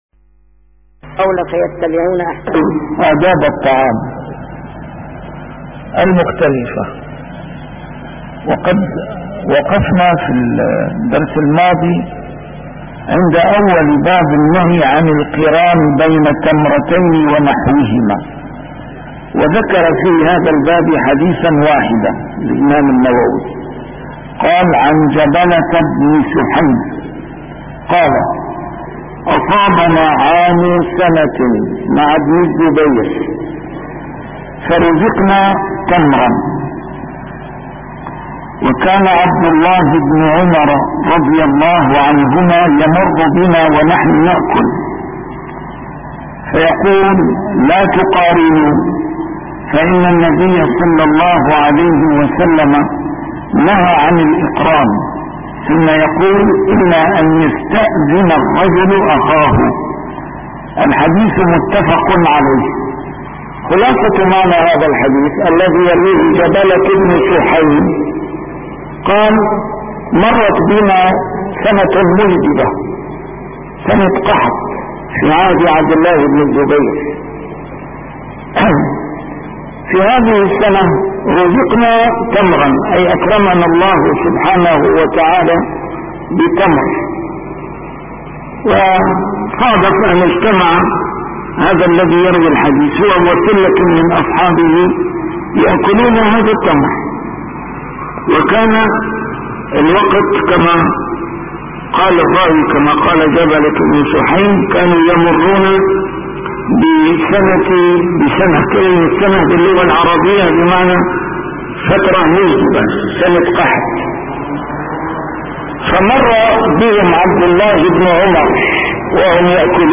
A MARTYR SCHOLAR: IMAM MUHAMMAD SAEED RAMADAN AL-BOUTI - الدروس العلمية - شرح كتاب رياض الصالحين - 646- شرح رياض الصالحين: النهي عن القران بين تمرتين